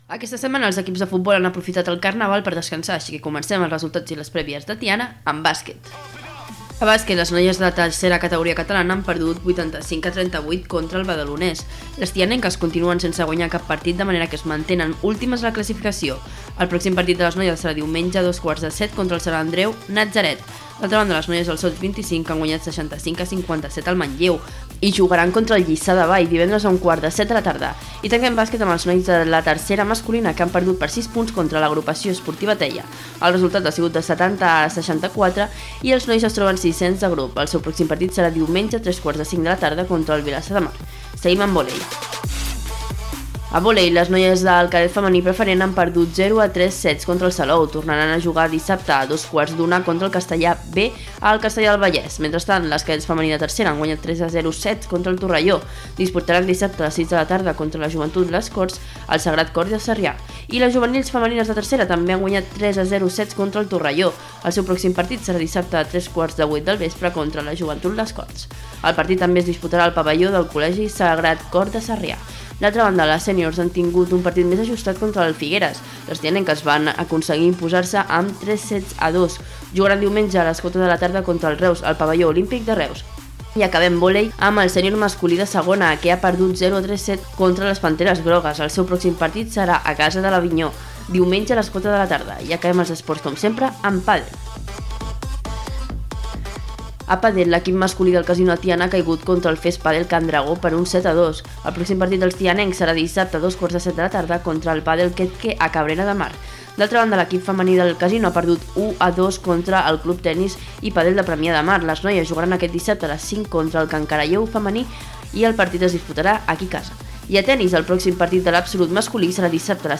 4520cb6eca5363bb57e1f1ce85012a0eea31dd79.mp3 Títol Ràdio Tiana (municipal) Emissora Ràdio Tiana (municipal) Titularitat Pública municipal Descripció Resultats esportius dels equips tianencs. Gènere radiofònic Esportiu